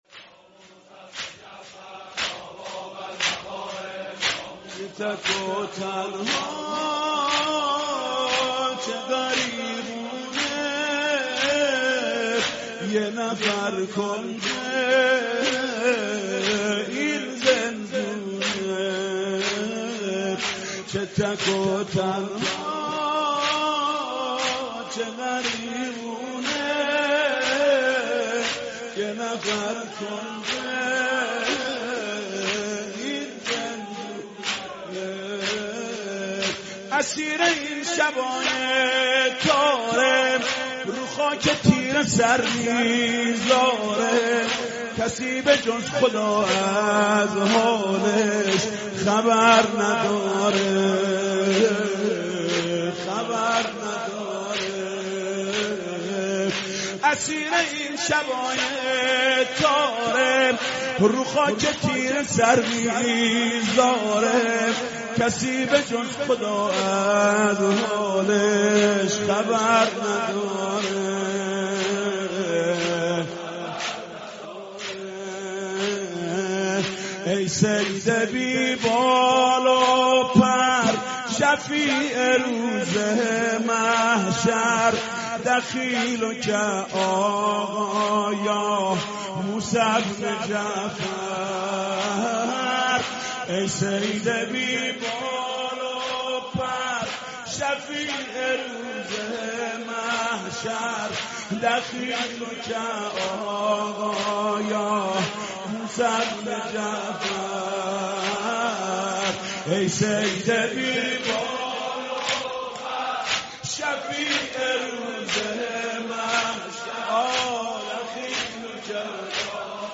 مرثیه خوانی شهادت امام موسی کاظم (ع)